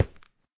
stone5.ogg